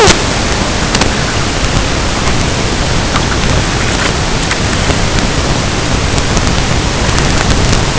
Hi, we are using nrf5340 with infineon IM69D120 mic our bandwidth is 20-2khz,we would need a 4KHZ sampling frequency with a 16 bit.
Our requirement is to record the audio (PCM data) and send it in ble while recording (live streaming) right now at 1280 kHz with 80 kHz sampling frequency recording and sending 228 bytes of data per payload up to 256 kb, but audio seems to be distorted.